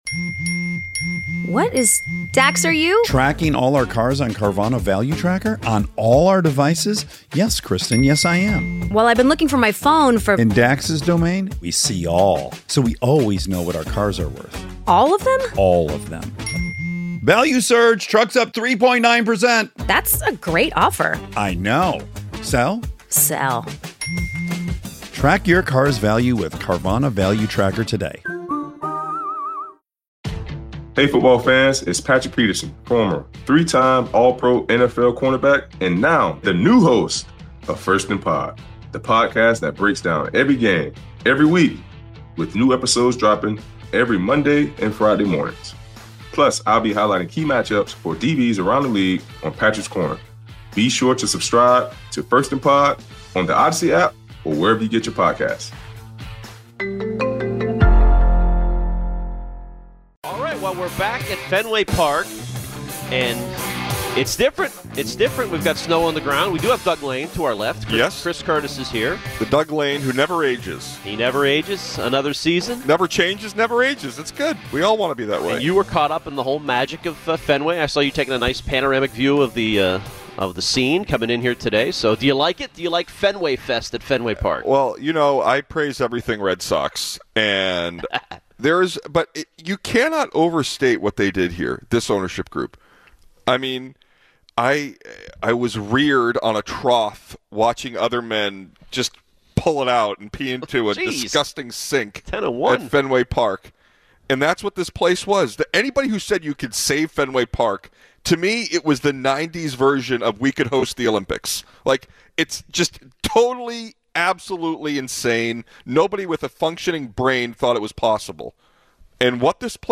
They are live at Fenway Fest this afternoon. They also speak to Sox pitcher Tanner Houck about his passion for baseball and the importance of veteran leadership.